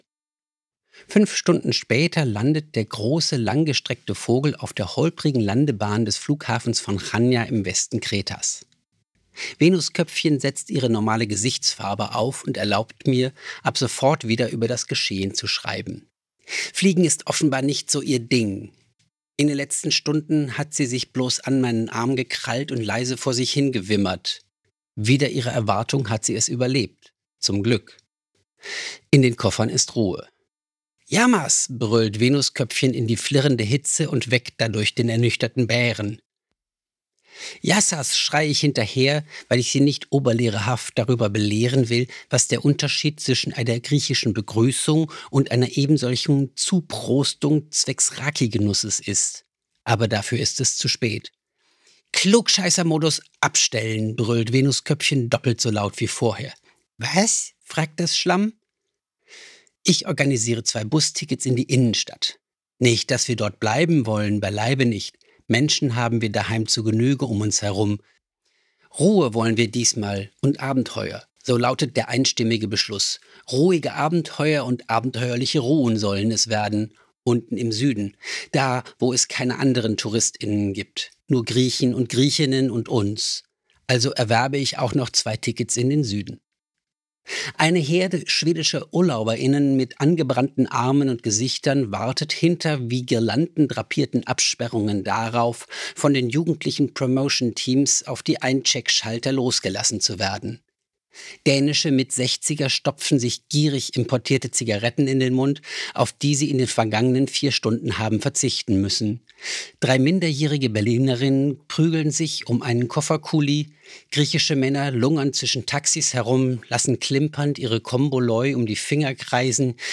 Kreta_Hoerprobe.mp3